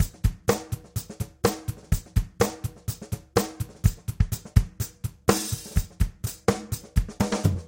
Tag: 125 bpm Chill Out Loops Synth Loops 1.29 MB wav Key : F